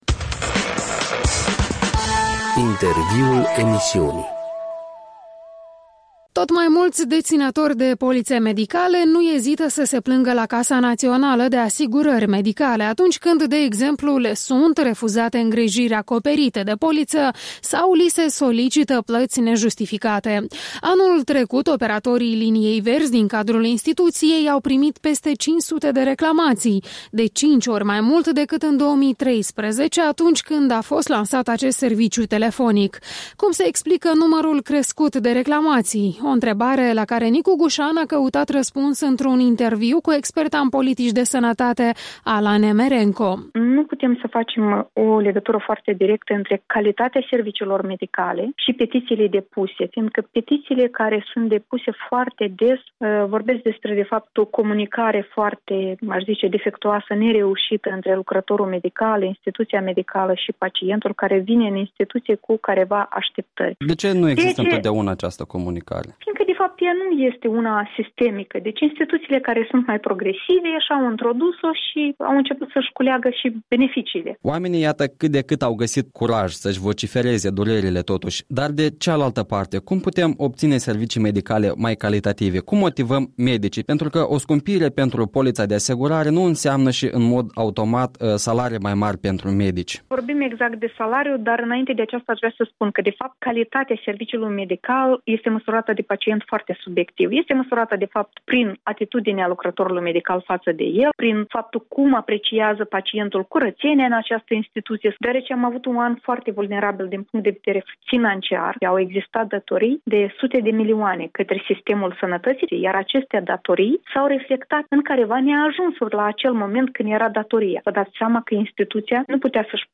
Un interviu cu experta în politici de sănătate.